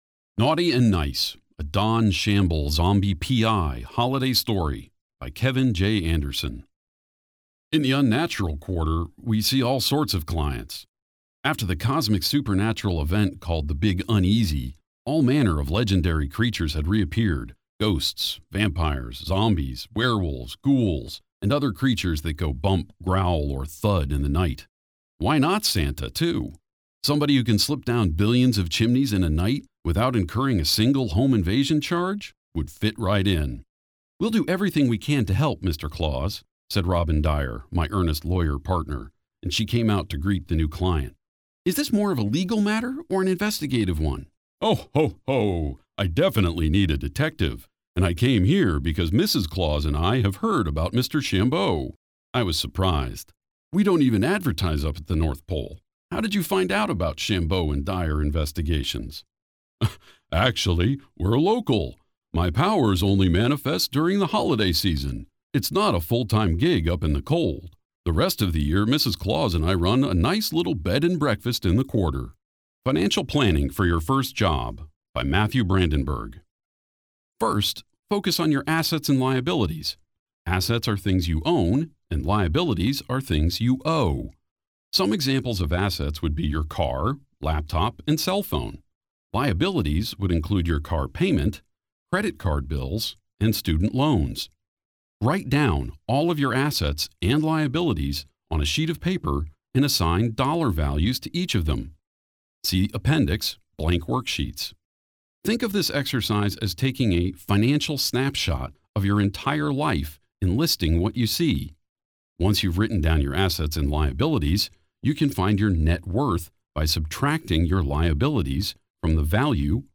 Male
English (North American)
Adult (30-50)
My voice is deep, resonant, authoritative, friendly, sonorous, confident, soothing, warm, understandable, knowledgeable, honest and confident
Audiobook Compilation
0410AUDIOBOOK_Demo.mp3